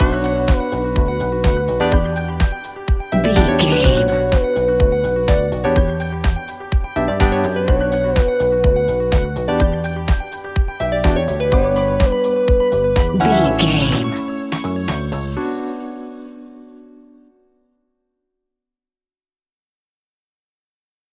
Aeolian/Minor
groovy
energetic
hypnotic
smooth
electric guitar
drum machine
synthesiser
electric piano
bass guitar
funky house